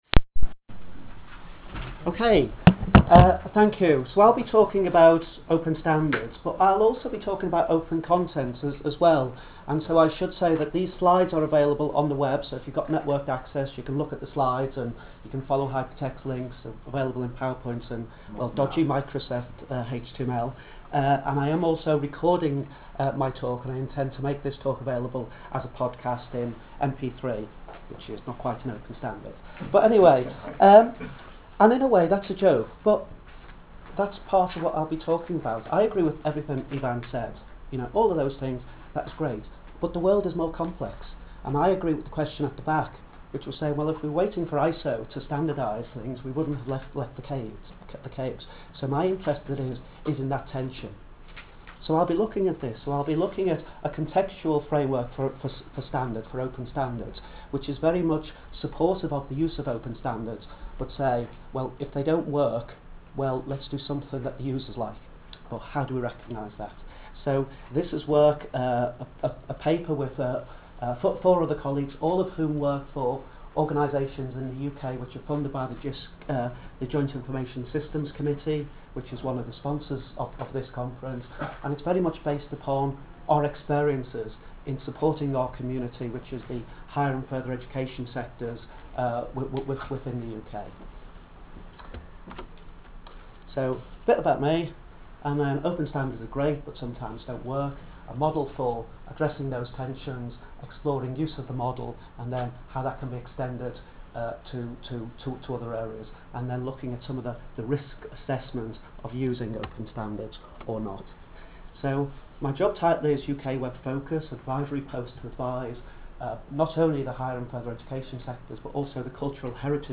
A recording of the talk was created using an iPod.